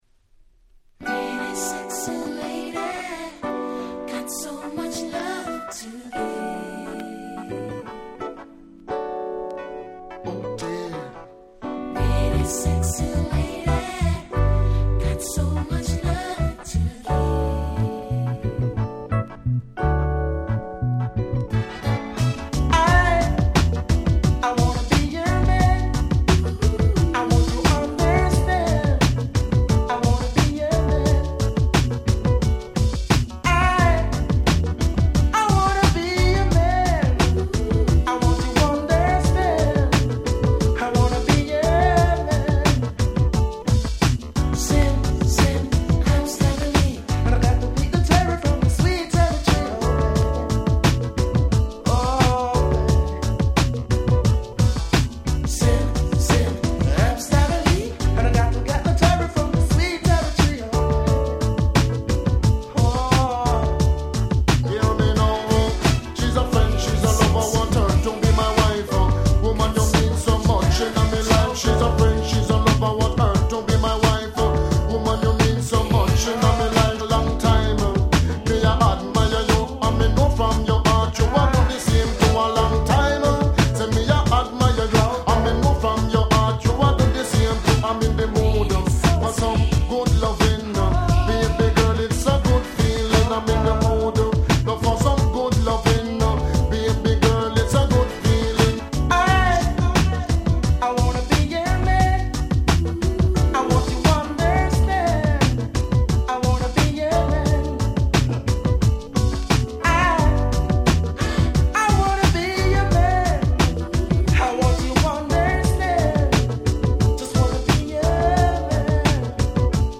94' Nice Reggae !!